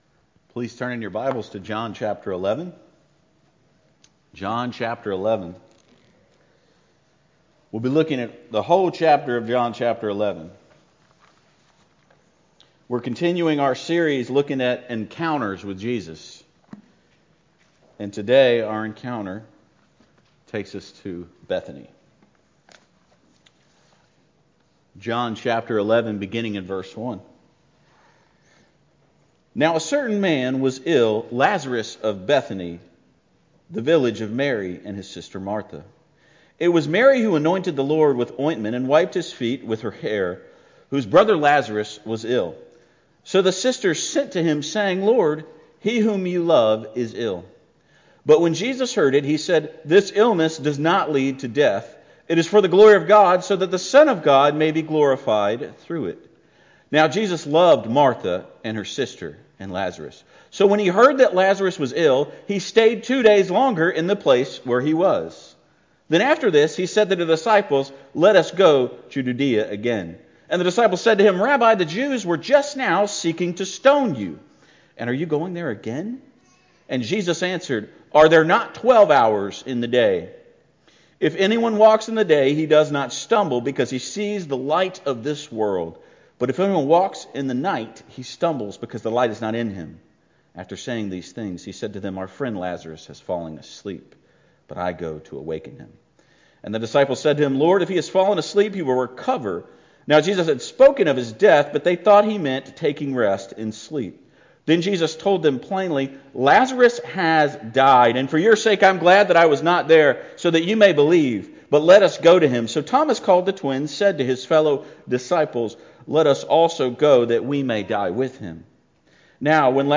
Encounters with Jesus Sunday Morning